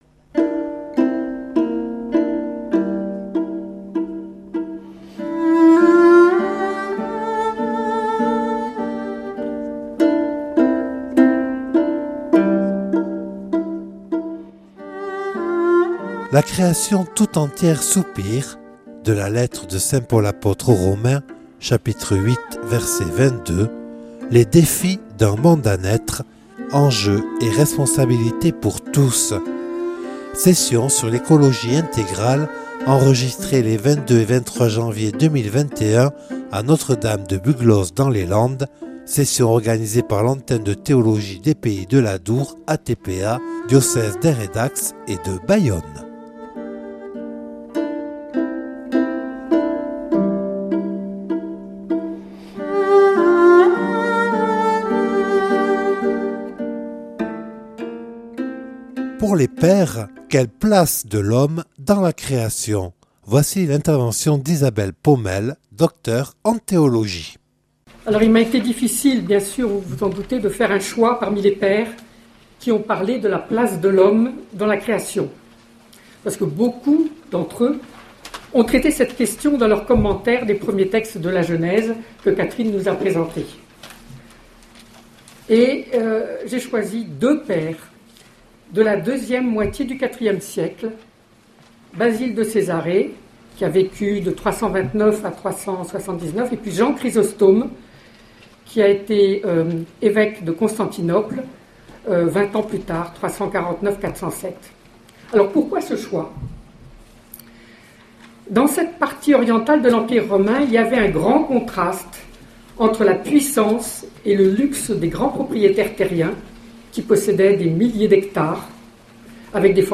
(Enregistré lors de la session sur l’écologie intégrale organisée les 22 et 23 janvier 2021 à Notre-Dame-de-Buglose par l’Antenne de Théologie des Pays de l’Adour).